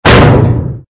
AA_drop_bigweight_miss.ogg